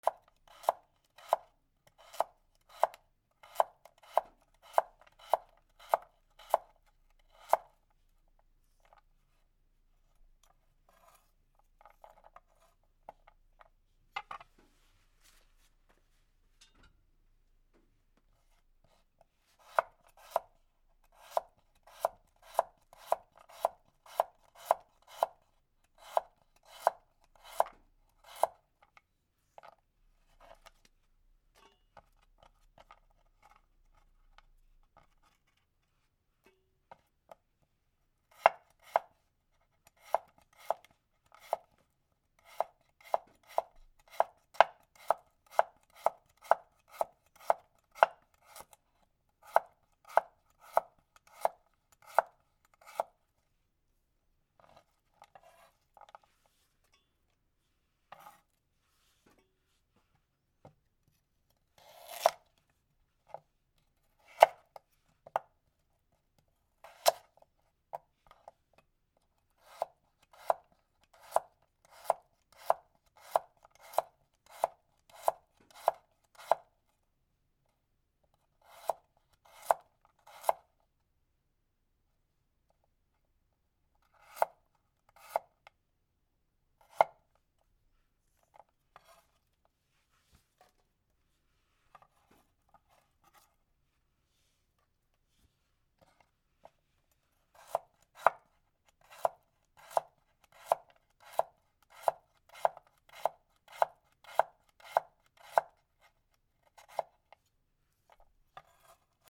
にんじんを切る 2